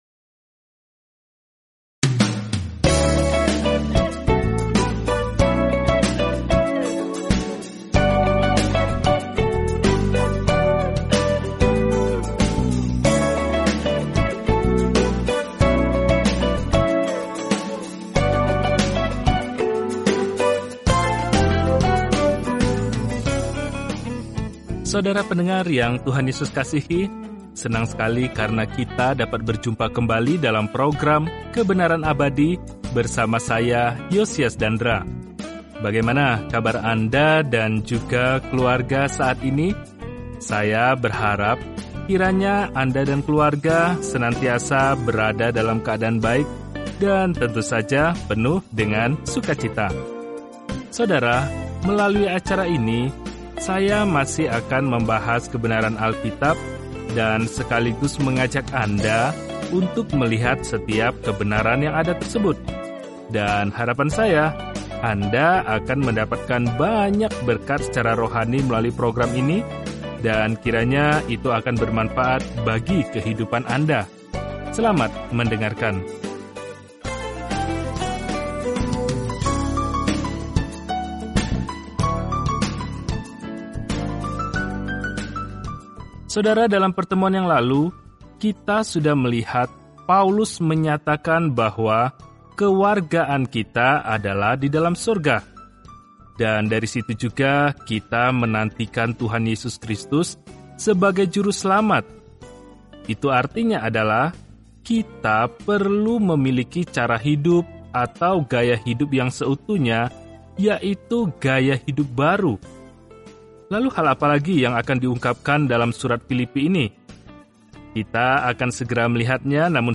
Firman Tuhan, Alkitab Filipi 3:21 Filipi 4:1-3 Hari 13 Mulai Rencana ini Hari 15 Tentang Rencana ini Ucapan “terima kasih” kepada jemaat di Filipi ini memberi mereka perspektif yang menyenangkan tentang masa-masa sulit yang mereka alami dan mendorong mereka untuk dengan rendah hati melewatinya bersama-sama. Telusuri surat Filipi setiap hari sambil mendengarkan pelajaran audio dan membaca ayat-ayat tertentu dari firman Tuhan.